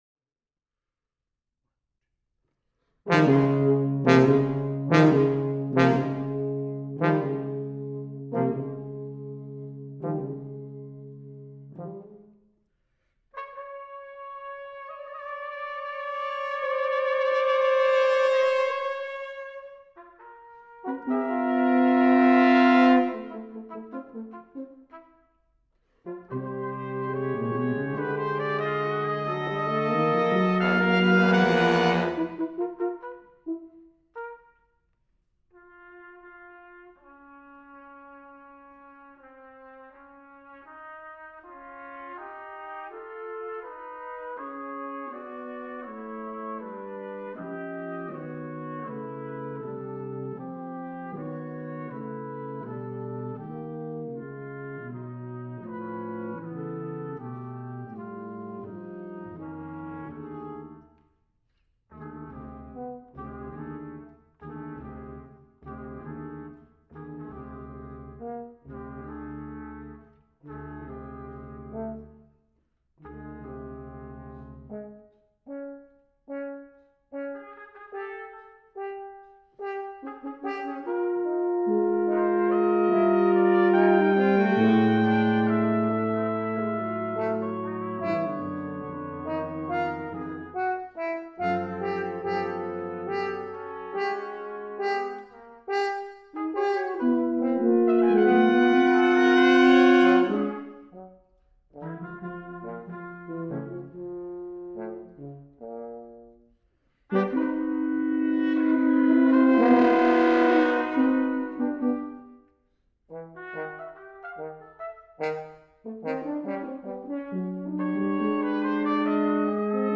Brass Quintet, music